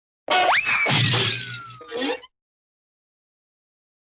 Звуки поскальзывания
Поскользнулся, улетел, поднялся